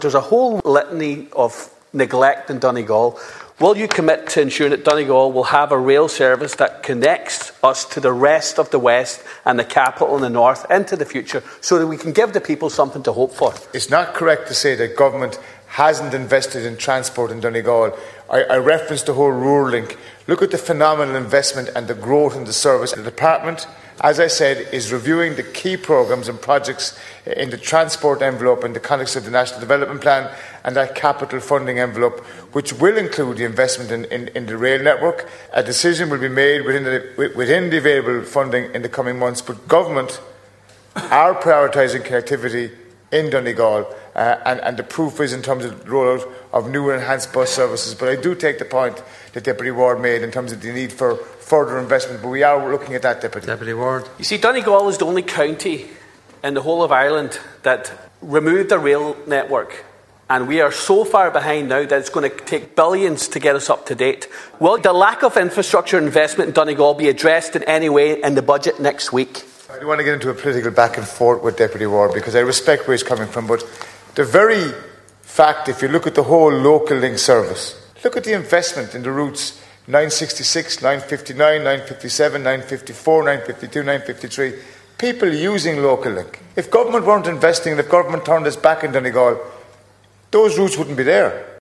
The government’s record on transport infrastructure in Donegal has come under scrutiny in the Dail.